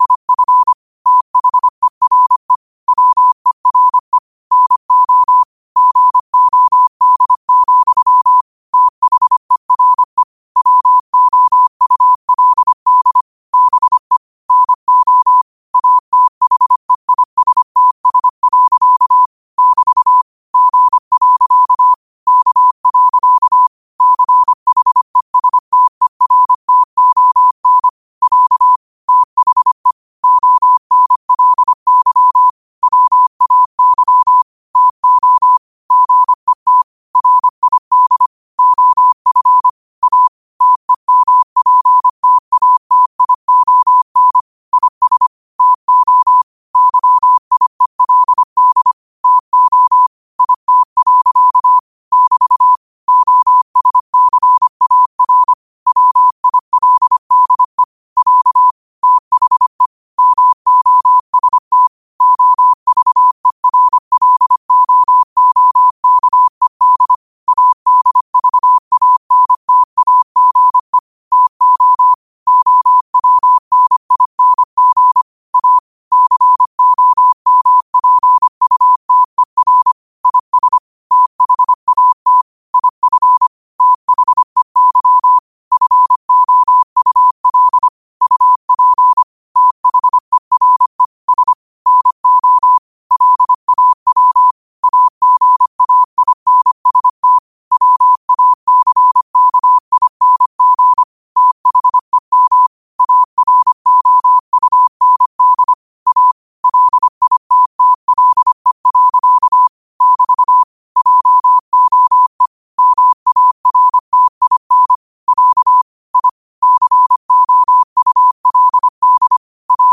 New quotes every day in morse code at 25 Words per minute.